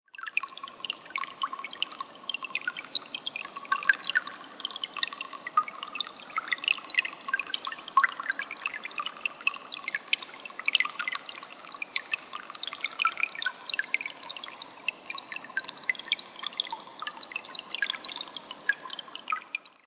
Murmuring Creek Sounds for Relaxation and Meditation MP3
The eerie and beautiful sound of a softly murmuring creek deep in the woods, accompanied by the sounds of wind and bare tree branches rubbing together in the early Spring. Use this soundtrack for relaxation, meditation, or sleep.
Murmuring-Creek-sample.mp3